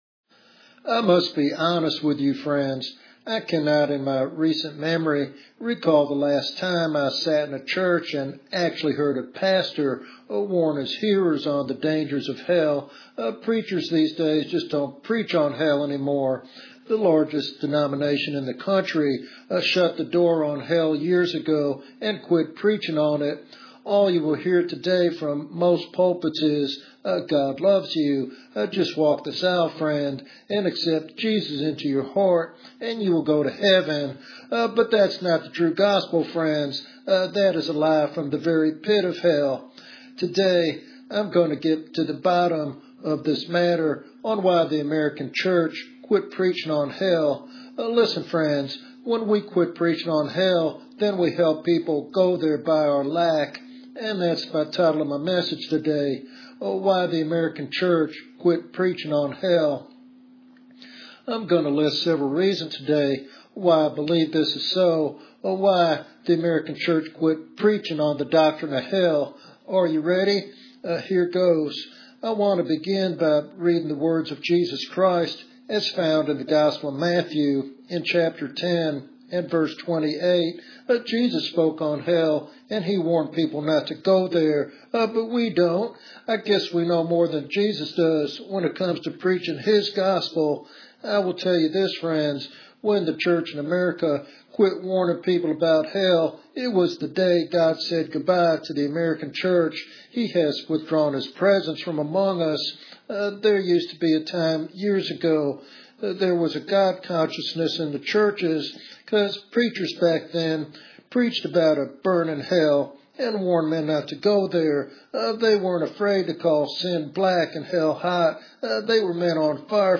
This sermon serves as a sobering wake-up call to the church to reclaim its biblical mandate.